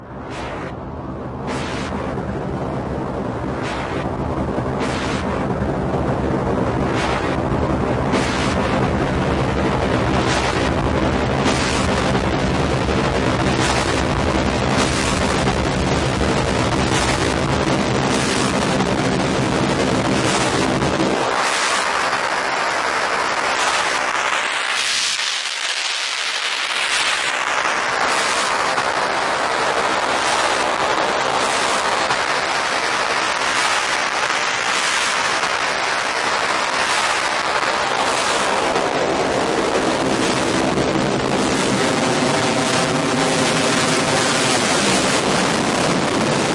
描述：模拟沙尘暴是用Triton和两个Electrix效果器，MoFX和Filter Factory制作的。 在Live中录音，通过UAD插件，Fairchild仿真器，88RS通道带和1073均衡器。 然后我编辑了这些结果，并把它们放在Kontakt中运行到Gating FX.
Tag: 失真 模拟滤波器 噪声